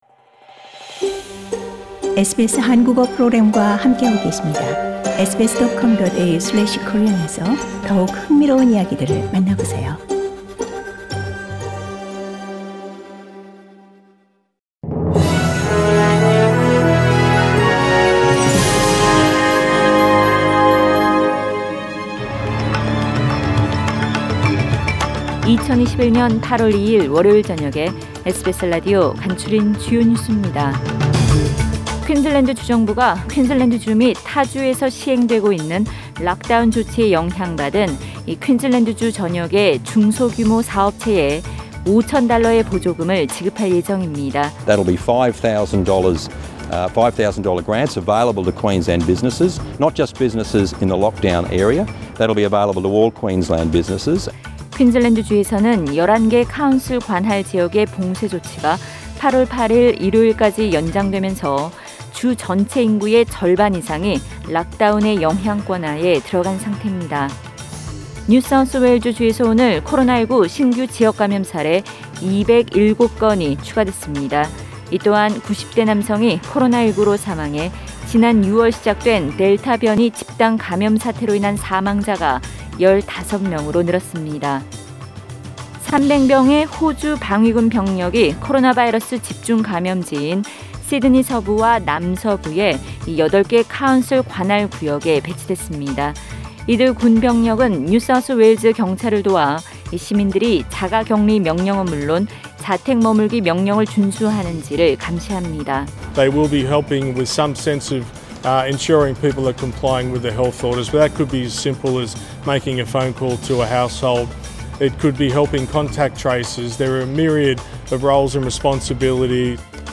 SBS News Outlines…2021년 8월 2일 저녁 주요 뉴스